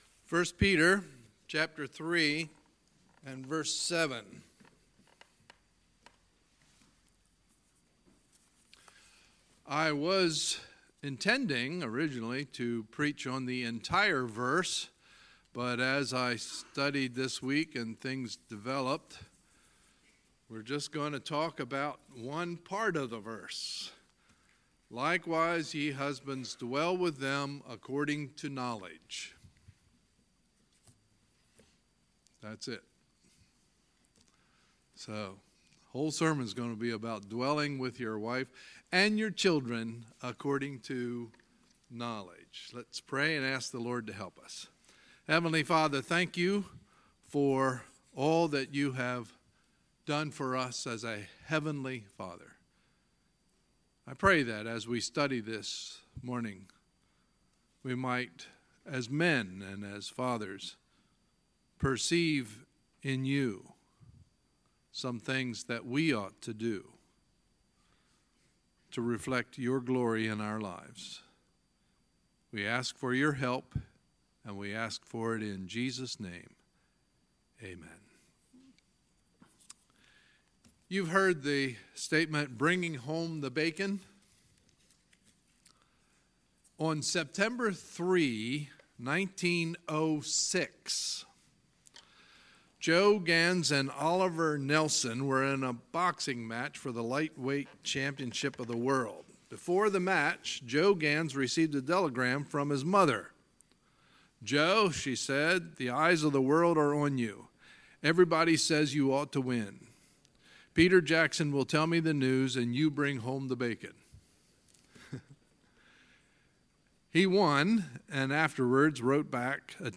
Sunday, June 17, 2018 – Sunday Morning Service